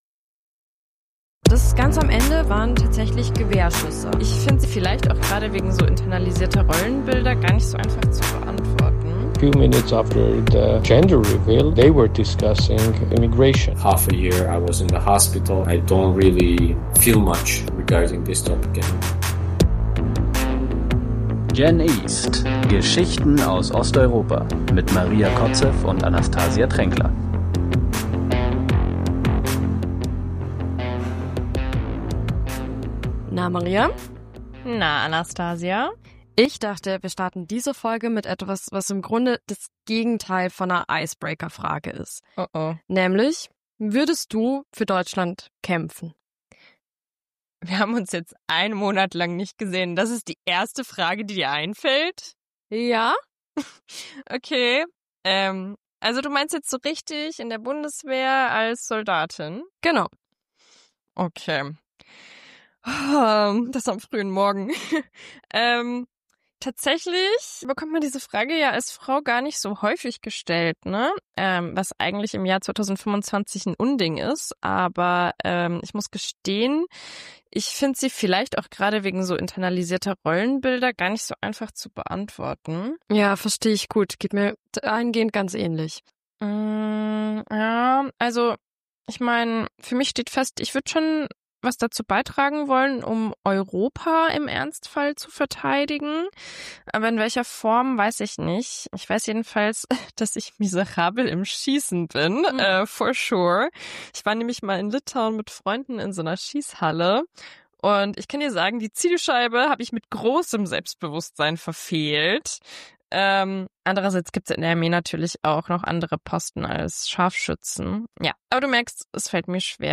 Wie Aserbaidschan Angst in Armenien verbreitet Konflikt mit Armenien: Wie Aserbaidschan Angst verbreitet Audio-Quellen: Trump-Töne: Youtube-Account des Weißen Hauses Nachrichtensprecherinnen: Die Tagesschau, Deutsche Welle, EWTN News Musik: lizenzfrei Mehr